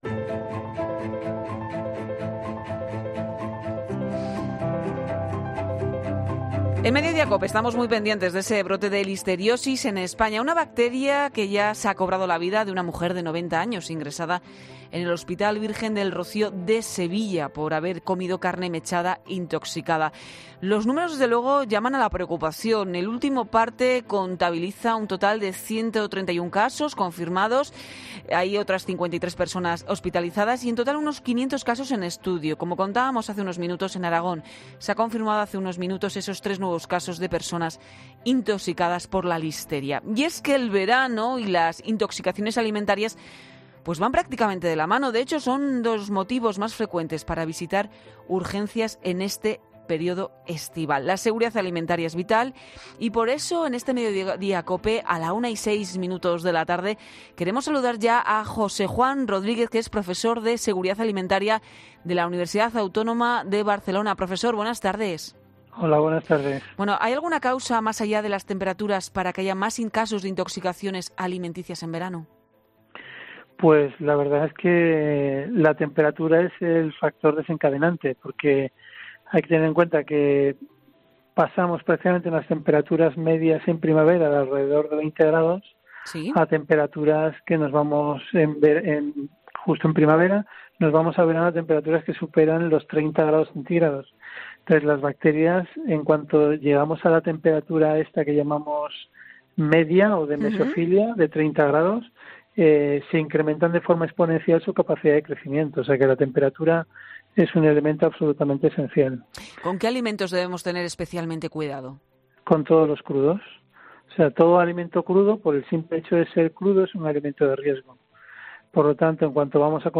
Un profesor de Sanidad Alimentaria de la Universidad Autónoma de Barcelona nos da las claves para prevenir posibles intoxicaciones.